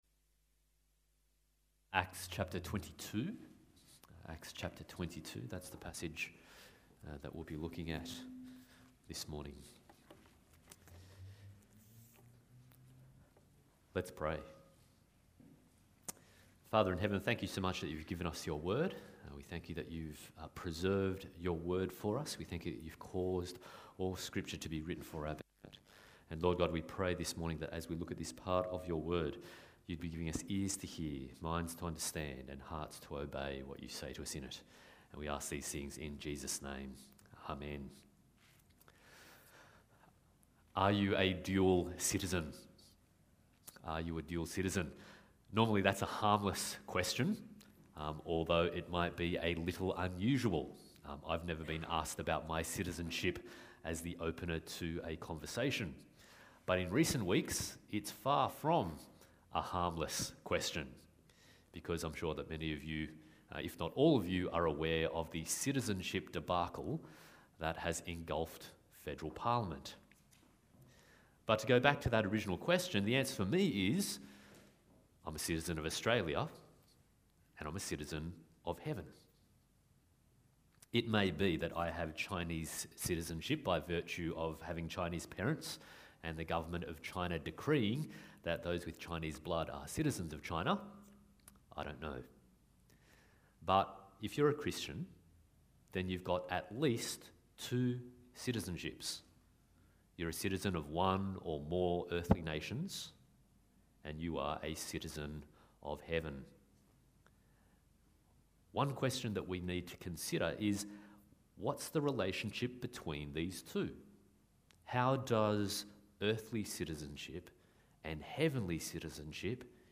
Acts 21-28 Passage: Acts 22:22-30, Acts 13:48-52, Daniel 2:36-47 Service Type: Sunday Morning